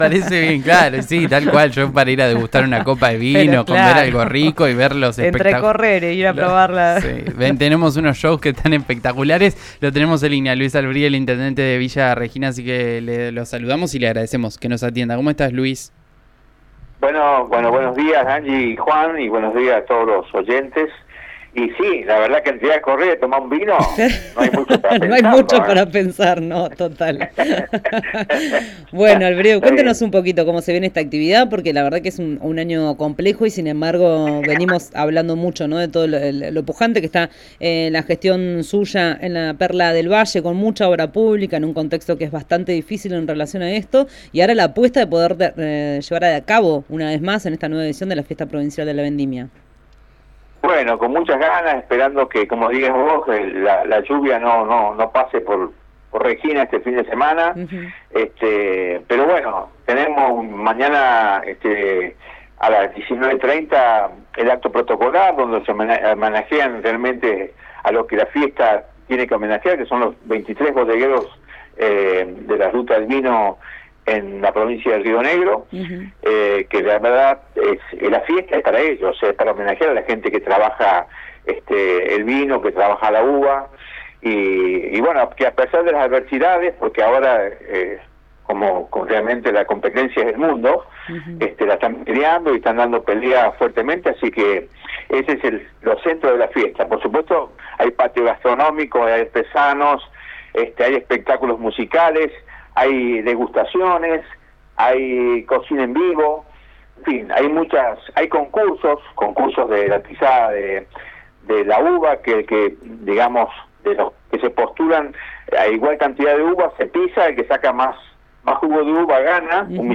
Luis Albrieu conversó sobre la fiesta con RÍO NEGRO RADIO.-